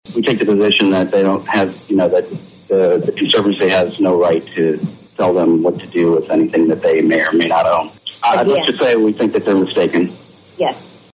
asserted in an August 2024 interview with Bartlesville Radio.